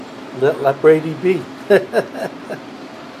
THIS MAN IN A PIZZA SHOP SAYS “LET BRADY BE”